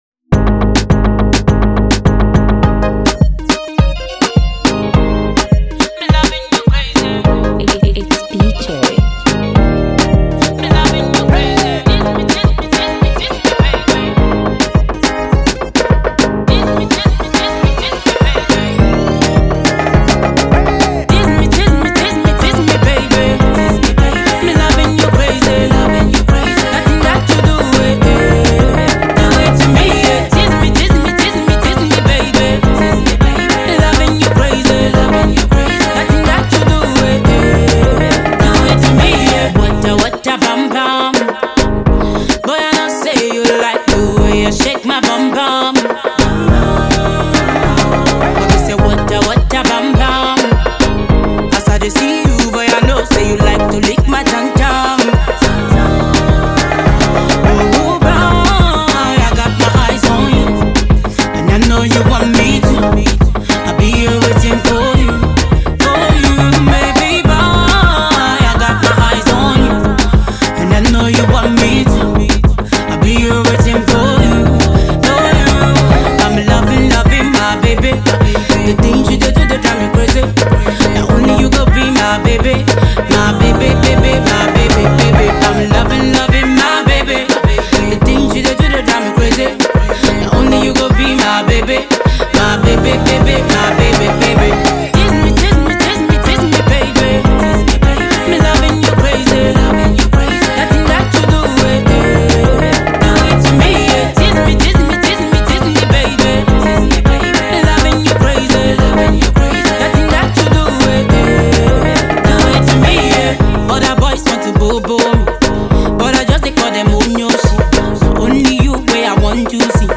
dancehall jam